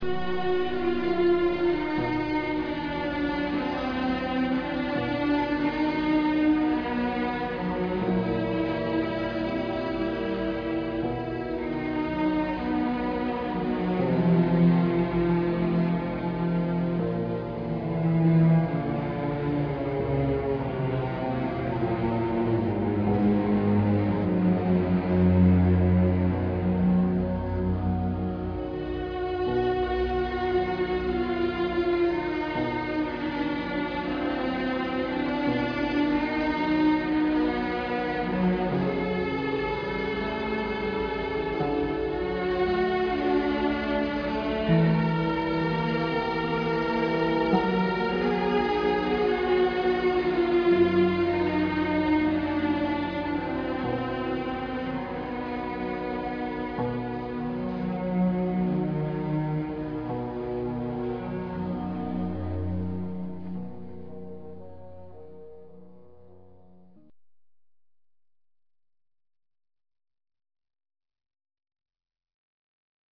Cello.au and arco:
cello.ra